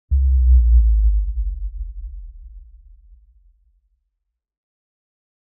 PixelPerfectionCE/assets/minecraft/sounds/mob/guardian/elder_idle3.ogg at mc116
elder_idle3.ogg